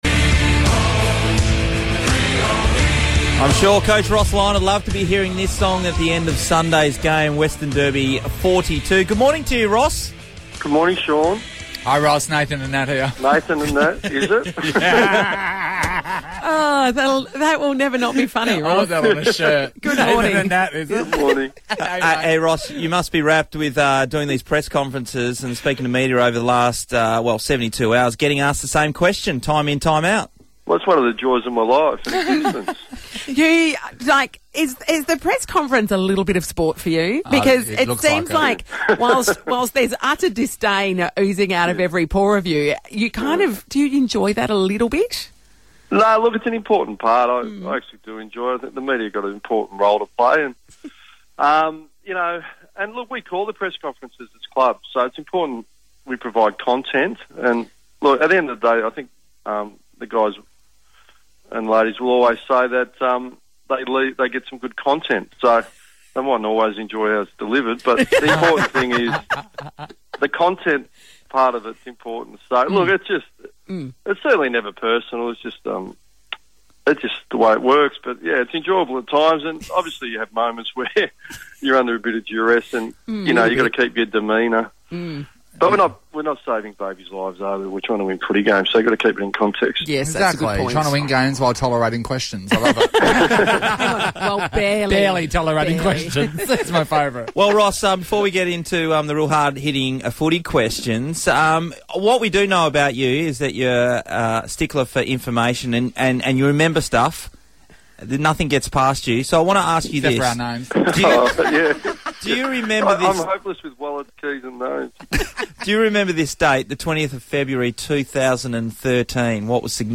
Ross Lyon spoke to Nova 937 about when and what he'll tweet for the first time